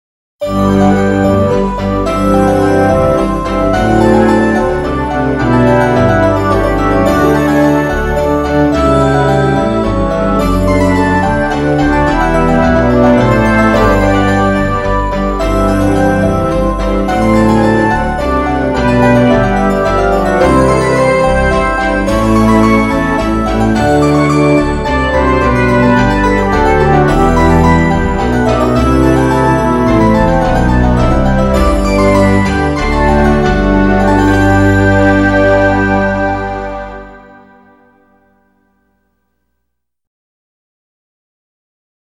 Жанр: Classic|Relax